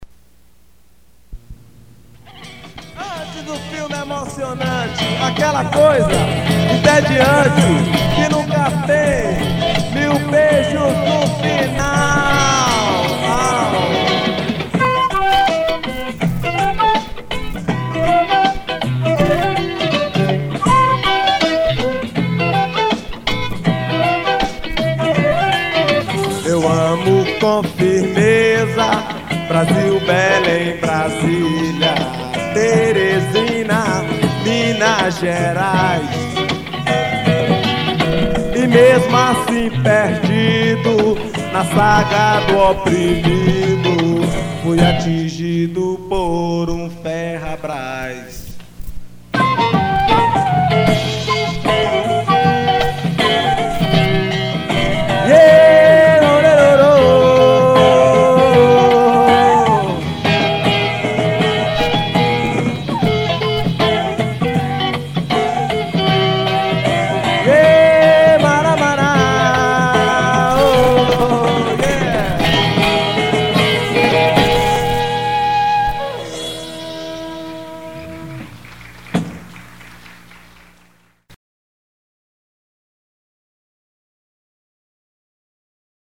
977   02:01:00   Faixa:     Rock Nacional